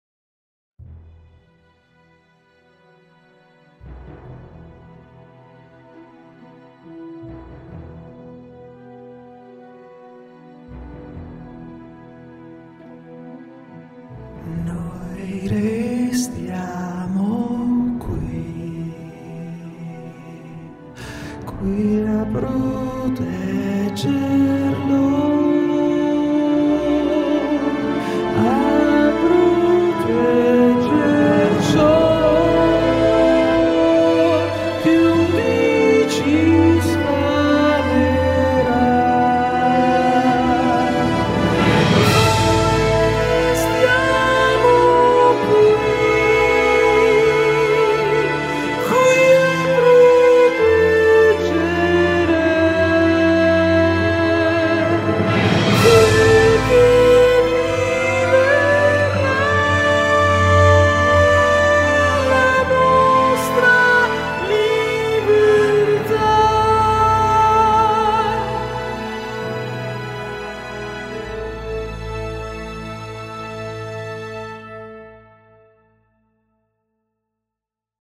LE VOCI GUIDA FEMMINILE SONO OTTENUTE TRAMITE SINTESI SONORA.
DONNE
soprano.mp3